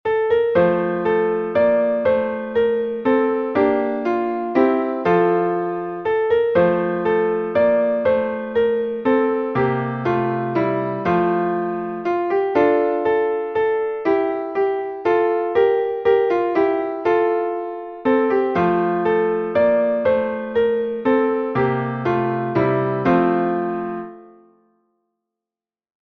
Key: F Major
Source: Welsh Carol